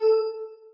button.ogg